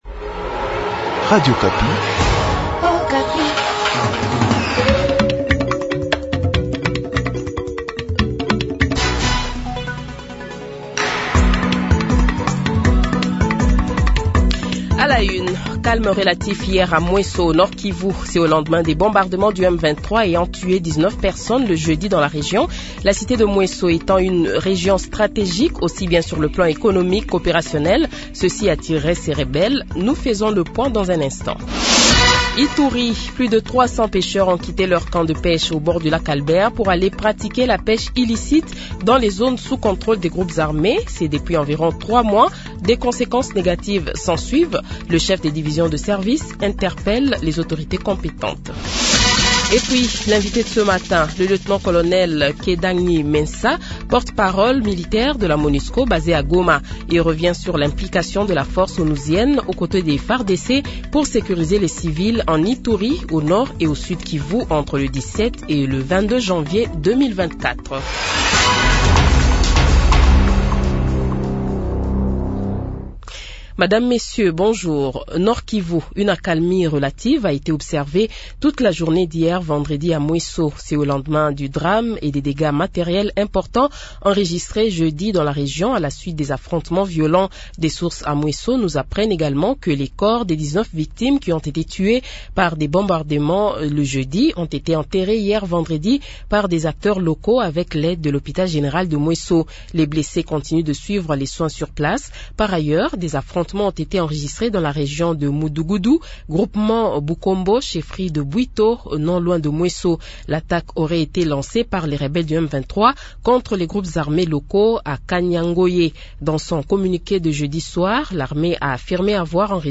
Journal Francais Matin